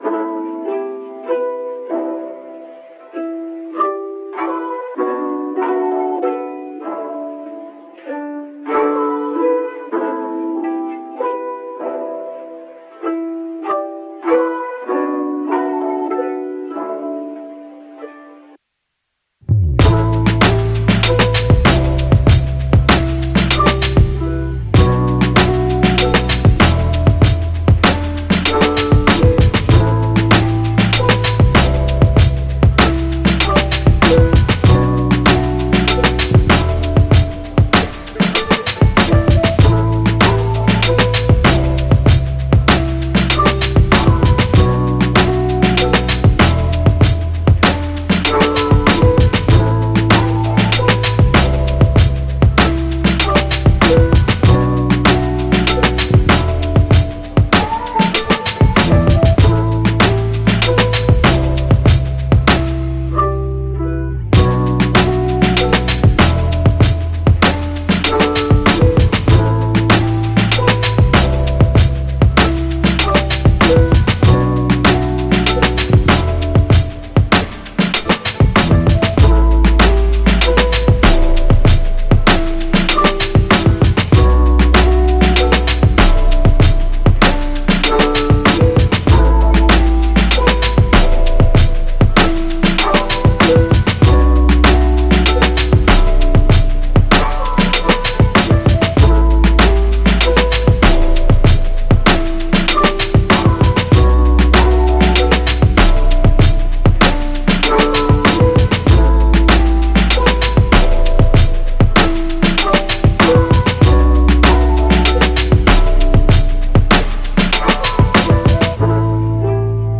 R&B
E Minor